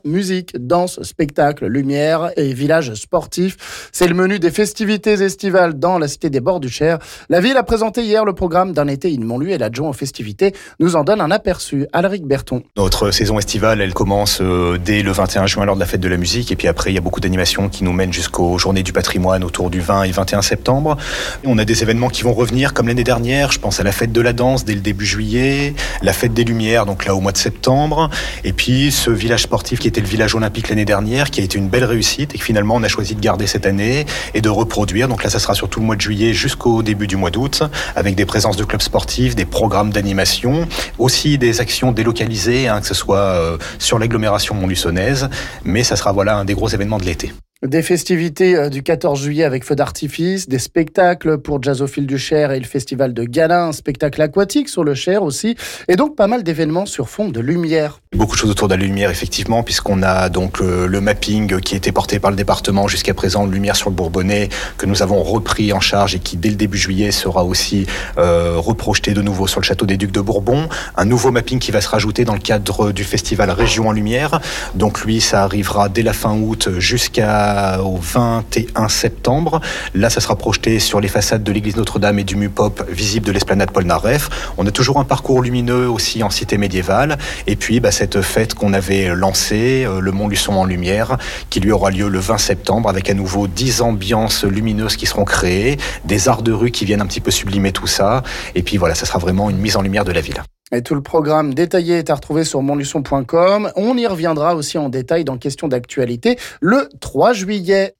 L'adjoint montluçonnais aux festivités nous en donne un aperçu...